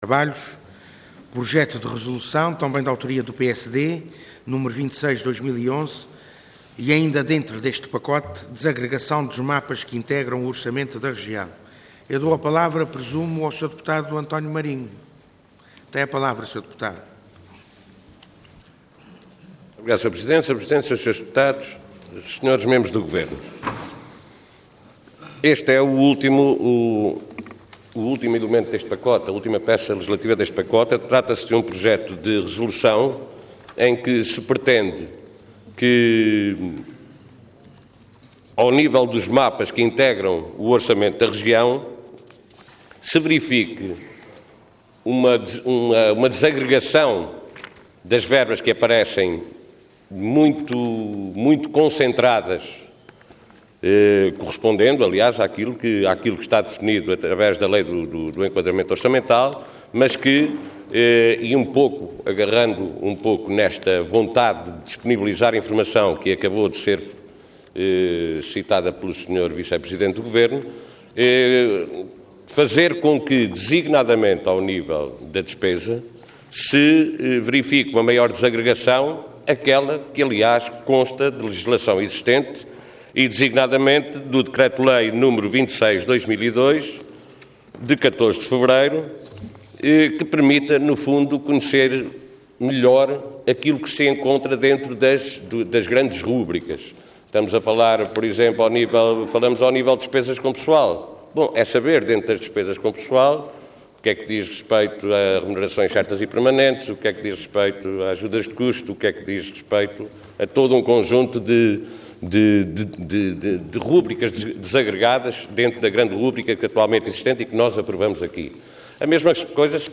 Website da Assembleia Legislativa da Região Autónoma dos Açores
Intervenção Projeto de Resolução Orador António Marinho Cargo Deputado Entidade PSD